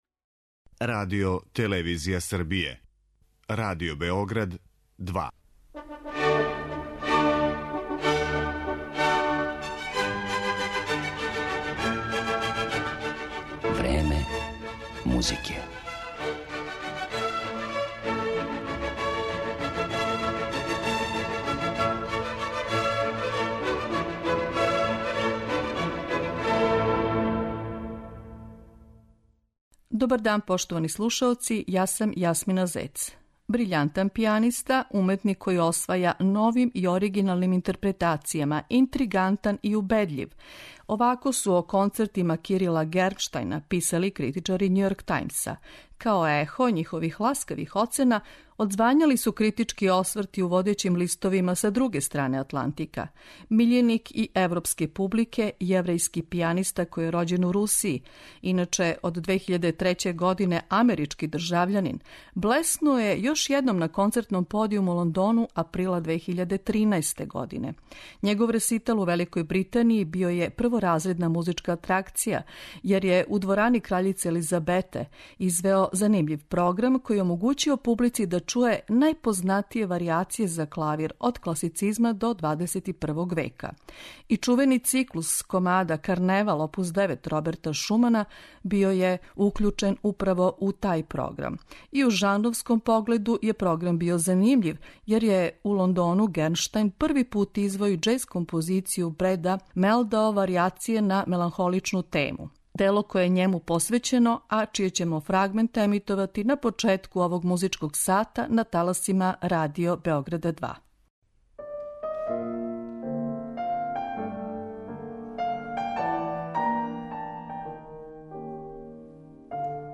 Овај јеврејски пијаниста стекао је америчко држављанство 2003. године.